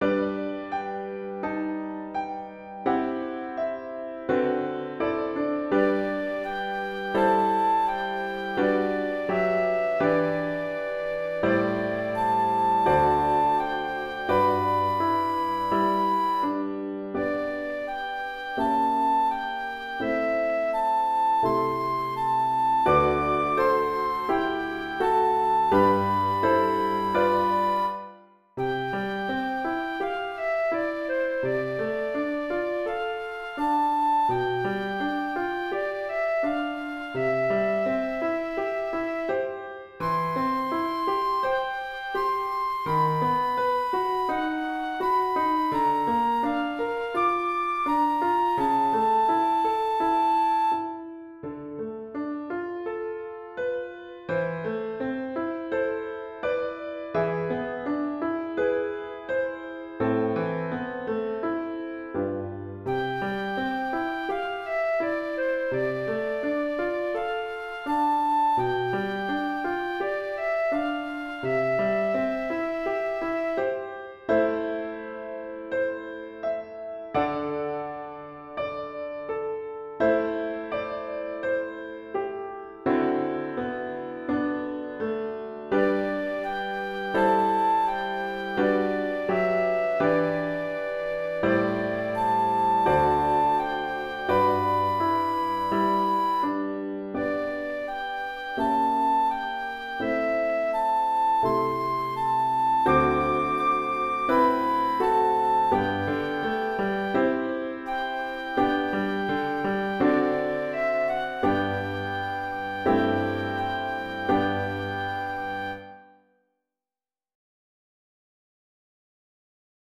Pour flûte et piano DEGRE CYCLE 1